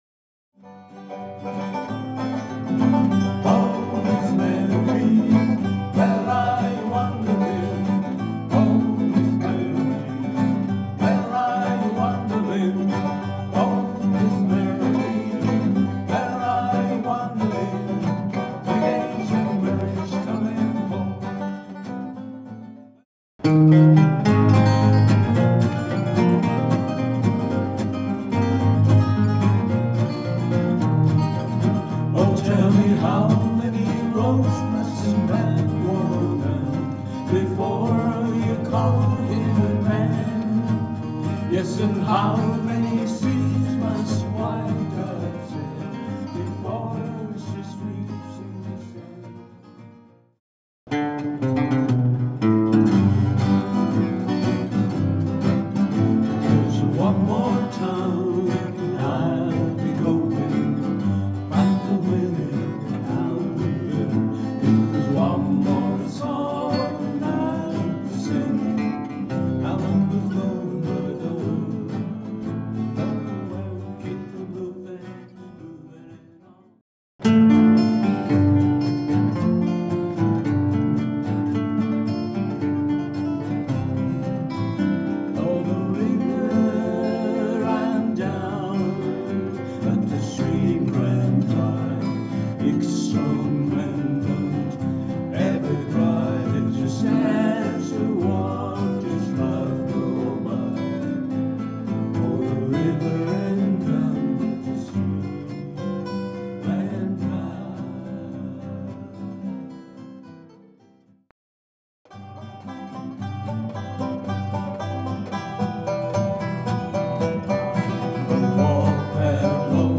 American Folk Song-Cover Band